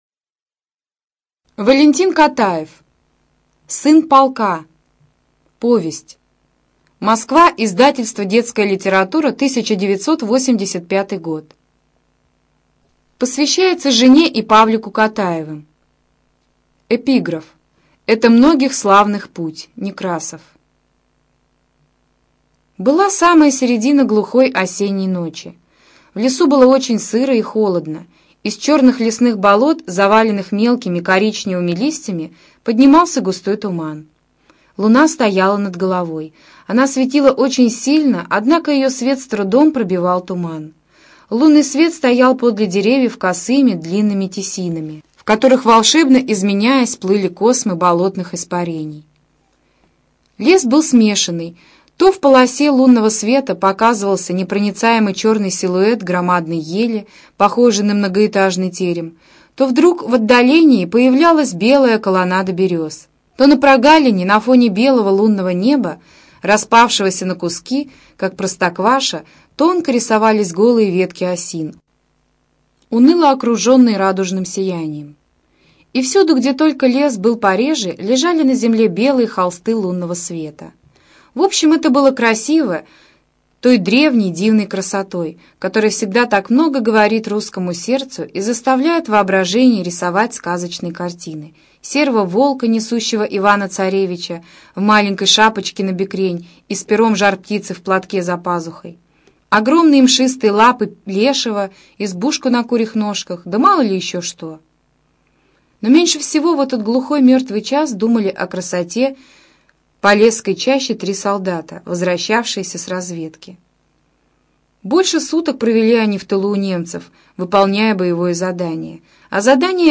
ЖанрДетская литература, Военная литература, Советская проза
Студия звукозаписиКемеровская областная специальная библиотека для незрячих и слабовидящих